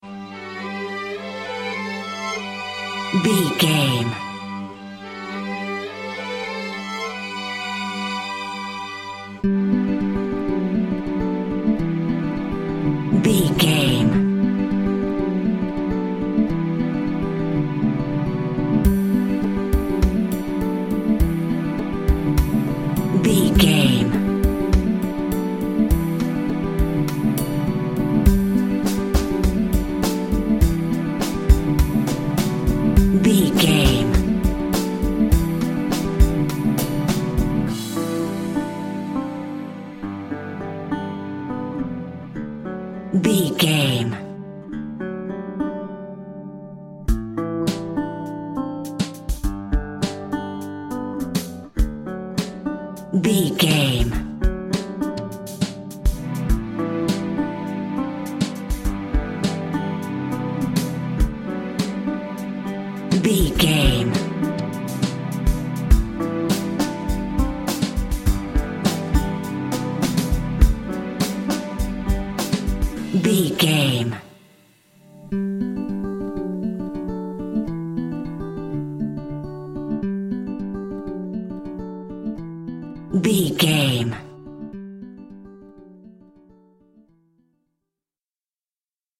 Uplifting
Ionian/Major
electronic
new age
trance
synths
Synth Pads
Synth Ambience